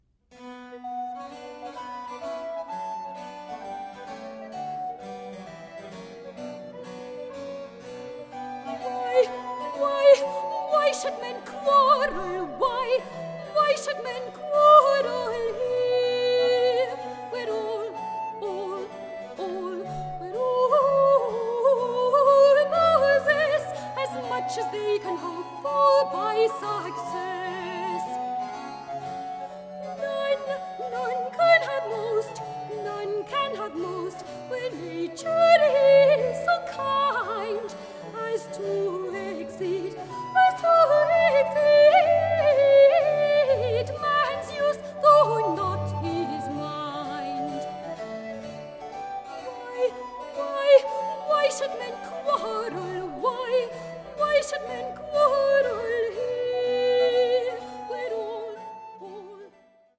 "Beautifully recorded."